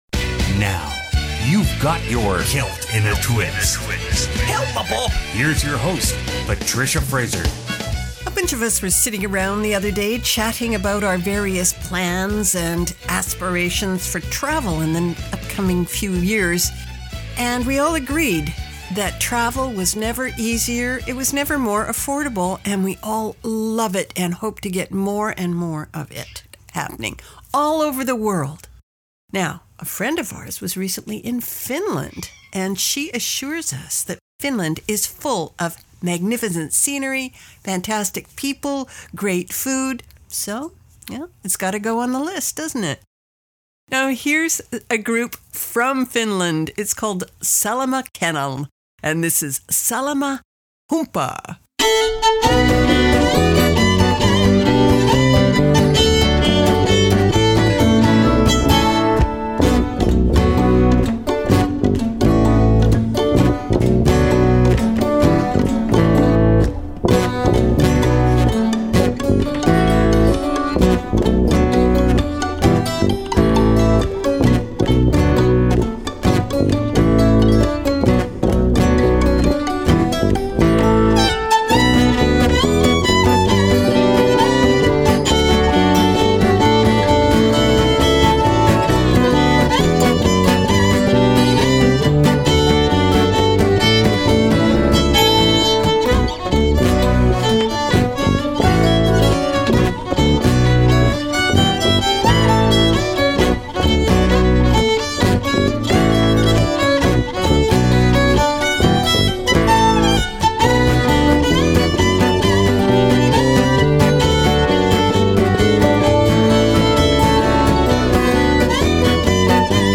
Canada's Contemporary Celtic Radio Hour
Weekly Program